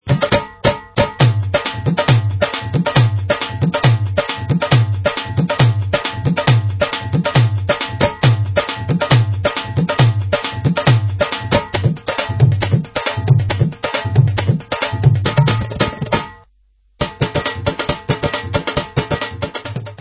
Dholak & Dhol 1